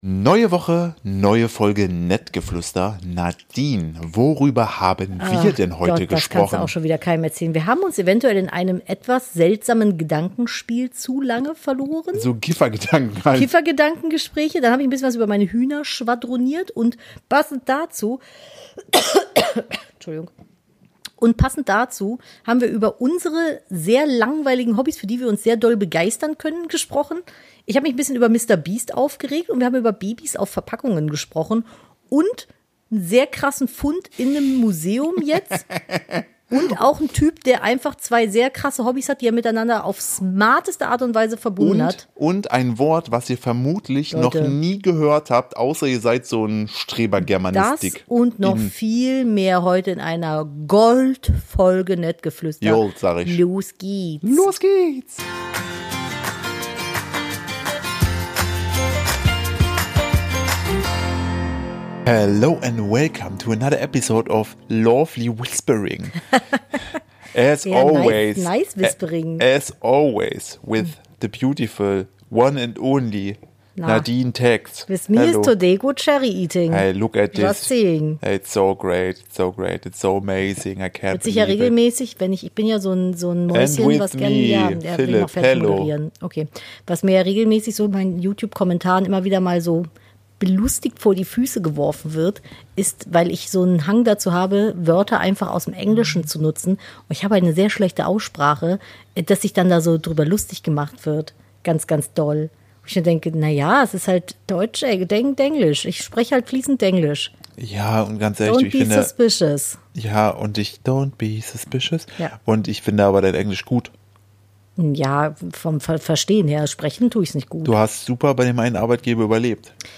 Komödie